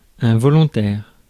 Ääntäminen
Vaihtoehtoiset kirjoitusmuodot (vanhentunut) determin'd Synonyymit dogged set steadfast strong resolved purposeful resolute strenuous unflinching Ääntäminen CA UK US UK : IPA : /dɪˈtɜː(ɹ)mɪnd/ GenAm: IPA : /dɪˈtɝmɪnd/